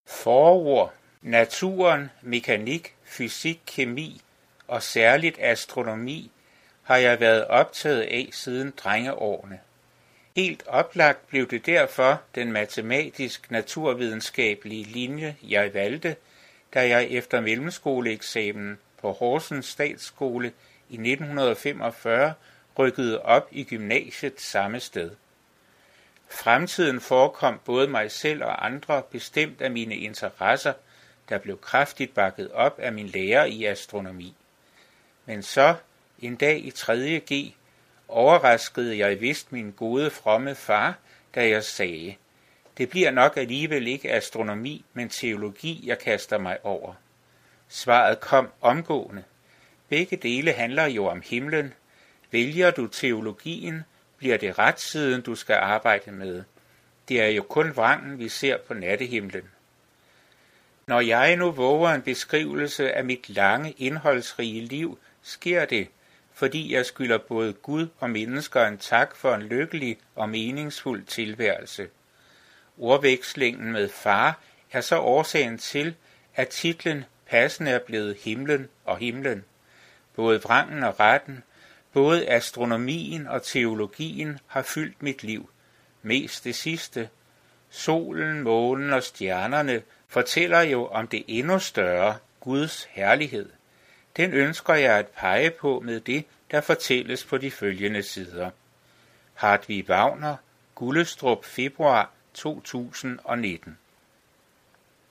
Lydbog